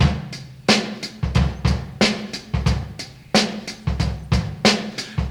91 Bpm Old School Drum Beat D Key.wav
Free drum beat - kick tuned to the D note. Loudest frequency: 1715Hz
91-bpm-old-school-drum-beat-d-key-nMH.ogg